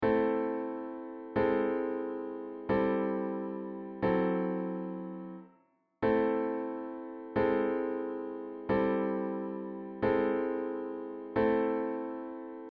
Am-Bass-Cliche
Am-Bass-Cliche.mp3